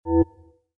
29_pressBtnSound.mp3